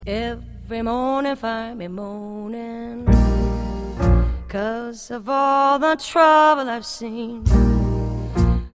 A vocal version
soul-jazz
B-flat minor : F3 to A4.
If you have a little lower female voice